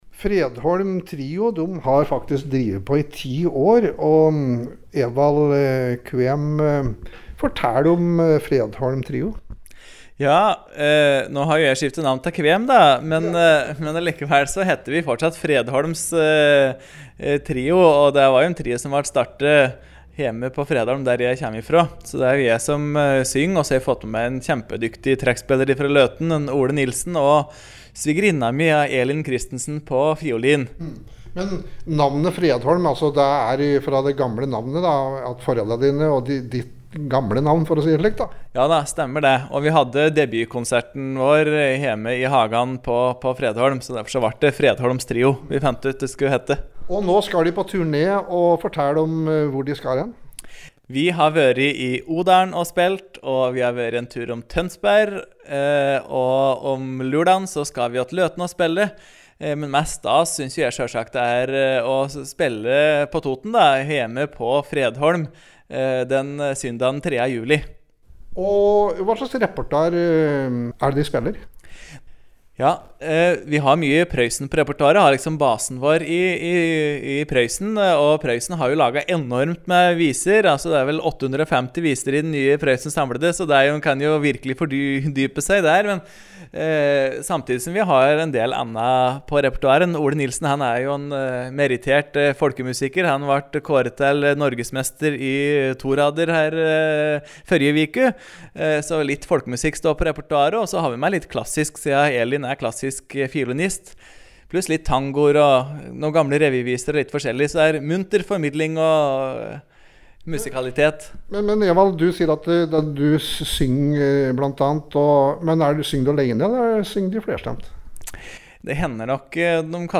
Innslag